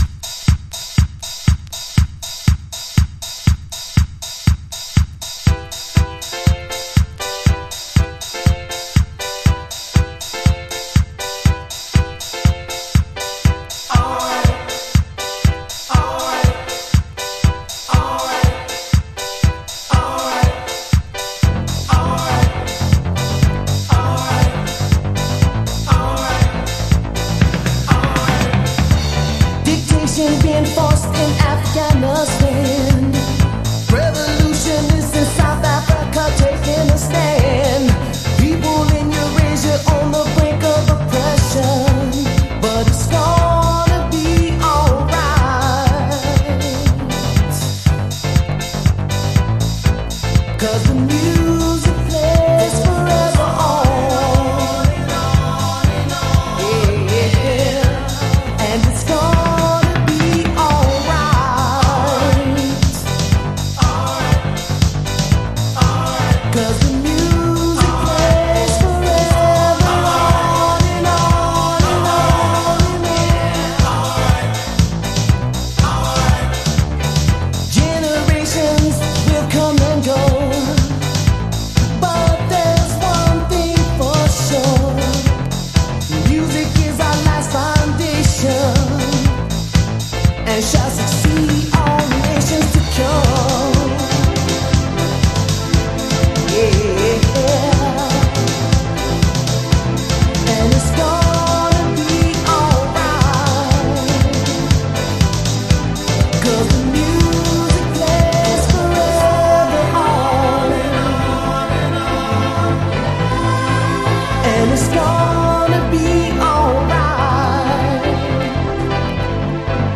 CHI-HOUSE古典
House Mix
Chicago Oldschool / CDH